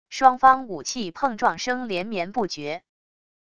双方武器碰撞声连绵不绝wav音频